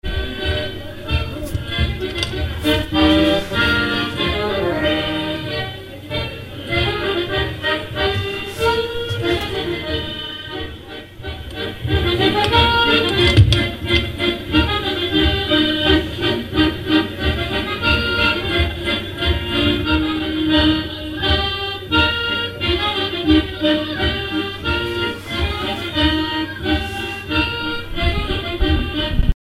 Polka piquée
airs pour animer un bal
Pièce musicale inédite